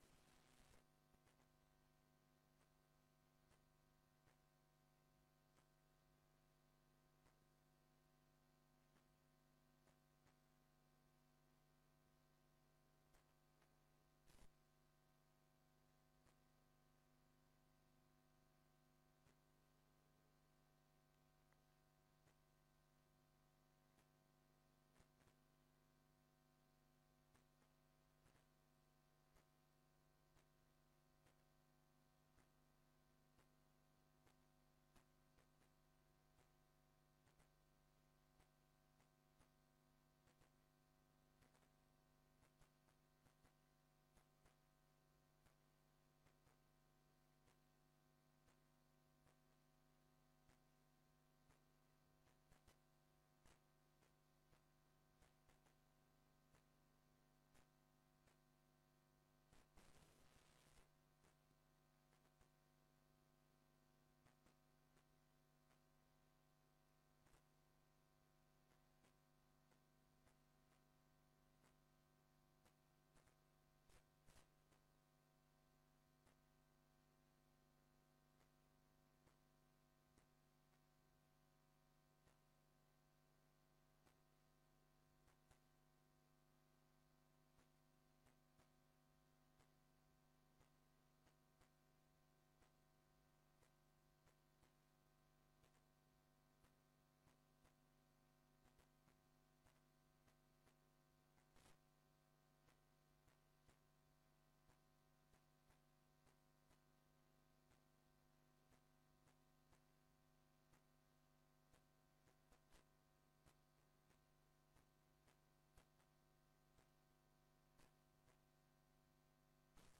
Locatie: Raadszaal